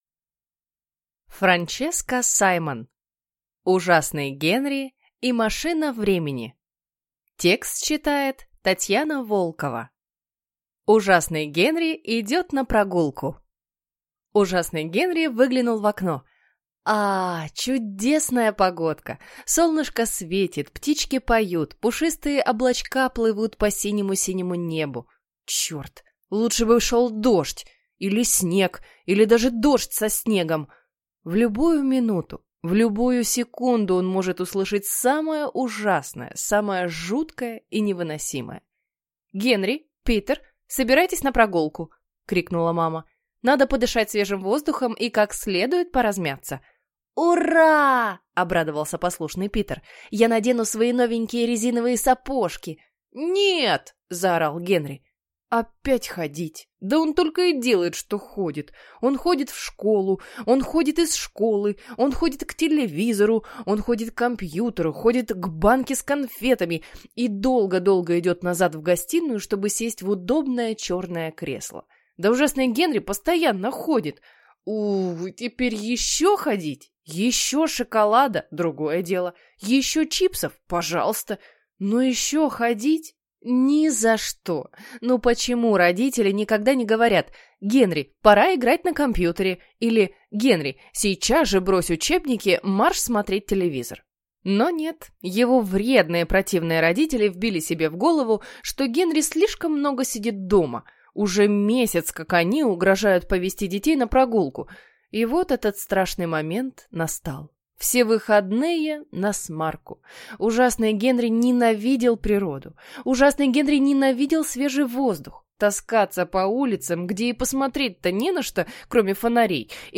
Аудиокнига Ужасный Генри и машина времени | Библиотека аудиокниг